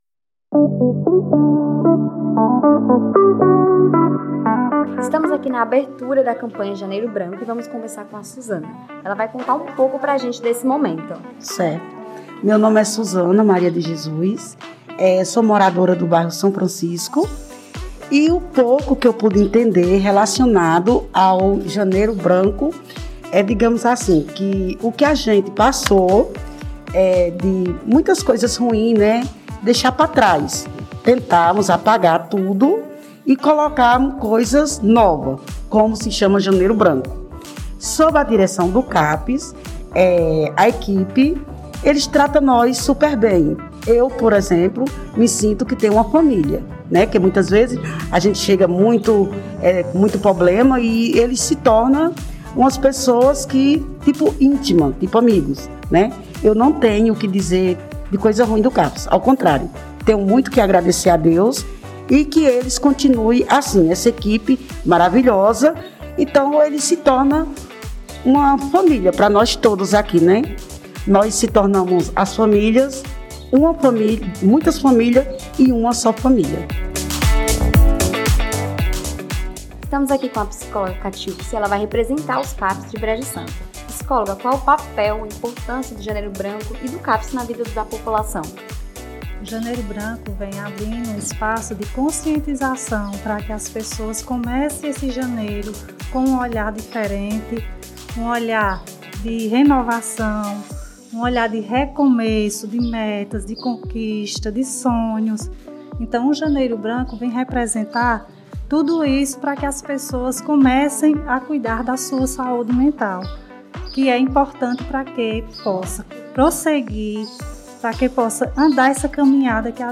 O evento aconteceu no CENTRO DE ATENÇÃO PSICOSSOCIAL INFANTO/JUVENIL – CAPSi e contou com a presença dos profissionais e população atendida pelas redes CAPS I, CAPS AD e CAPS i.
Entrevistas_Janeiro_Branco1.mp3